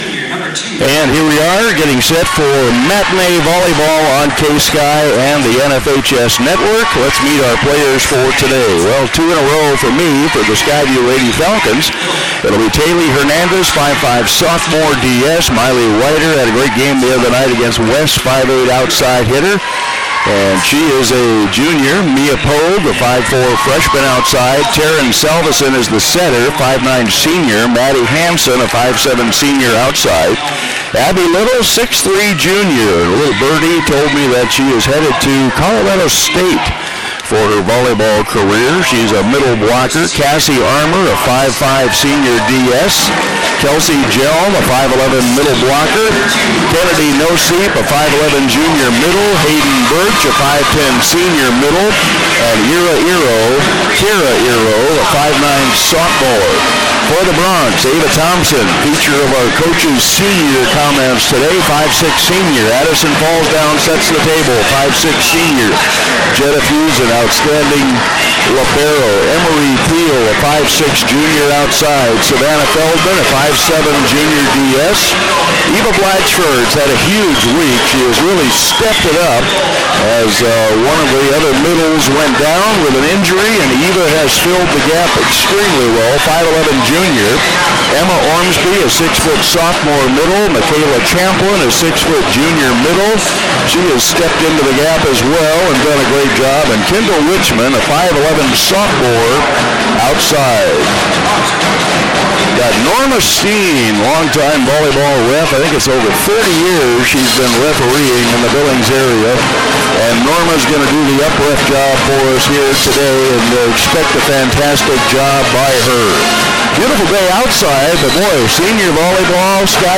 Senior volleyball @ Skyview, (slightly overmodulated)